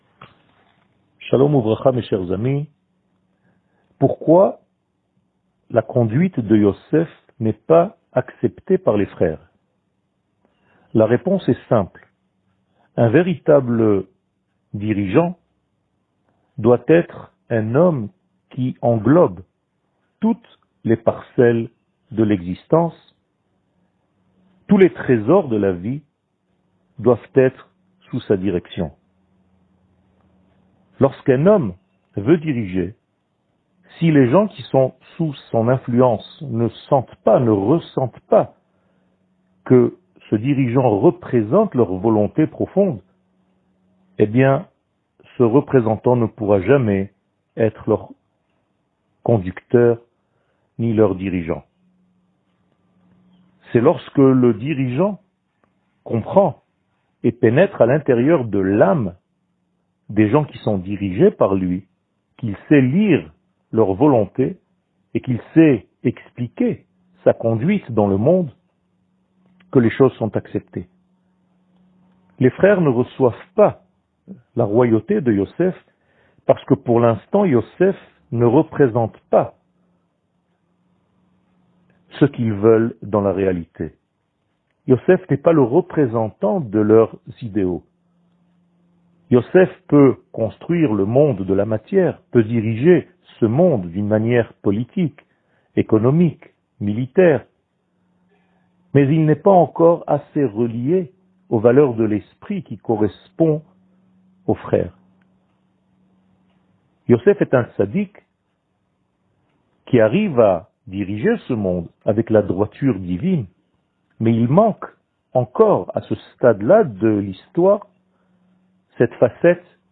שיעור מ 09 דצמבר 2020